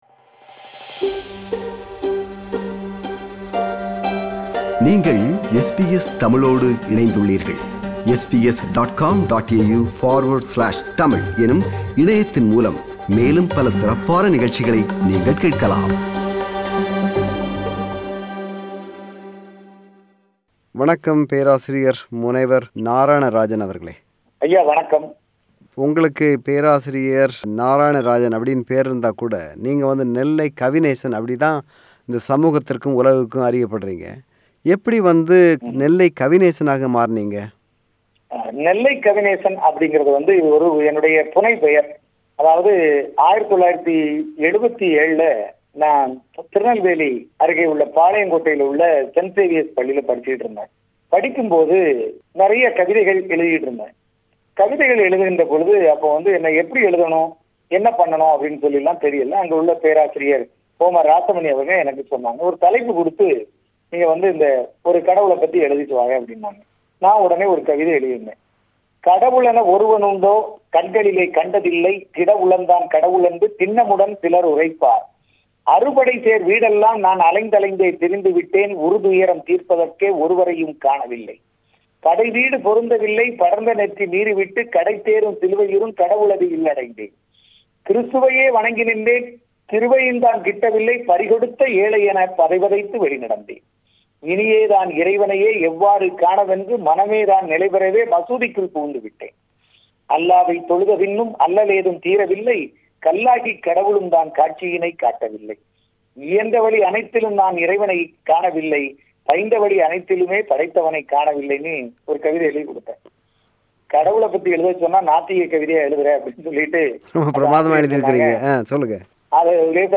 அவரை சந்தித்துப் பேசுகிறார்